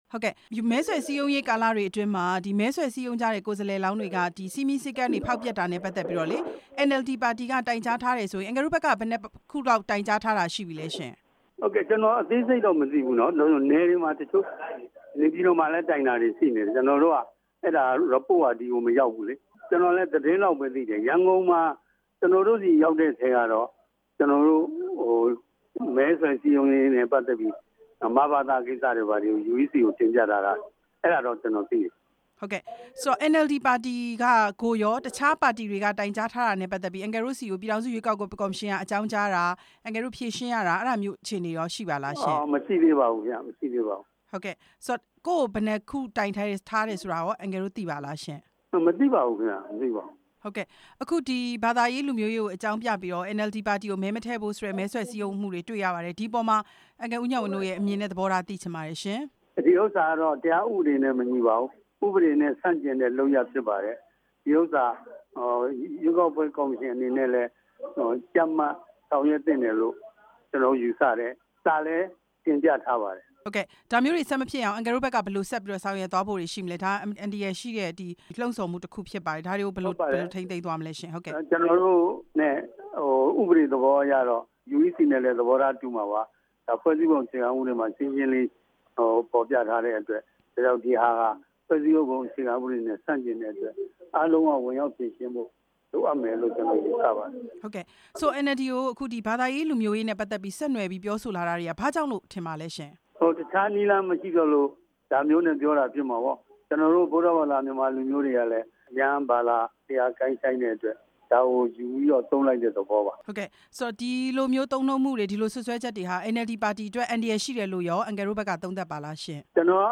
NLD ပါတီကို မဲမပေးဖို့ စည်းရုံးတာတွေရှိနေတဲ့အကြောင်း ဦးဉာဏ်ဝင်းနဲ့ မေးမြန်းချက်